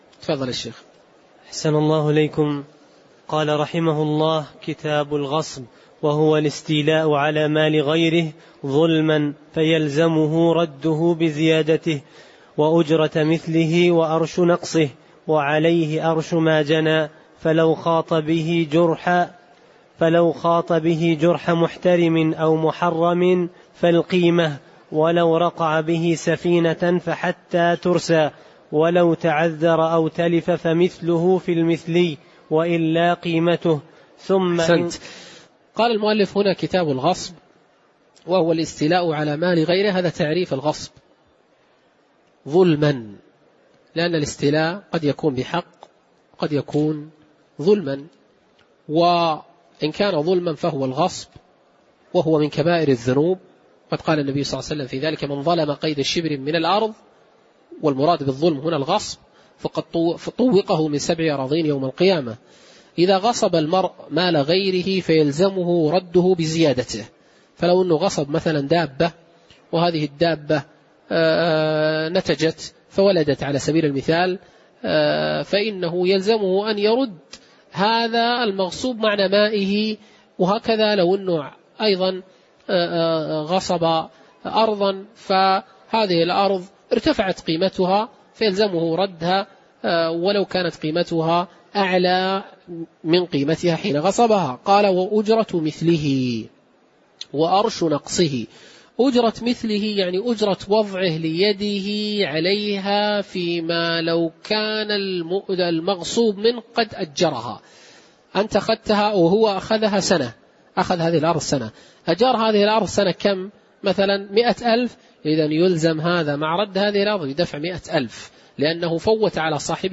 تاريخ النشر ١٩ شوال ١٤٣٩ هـ المكان: المسجد النبوي الشيخ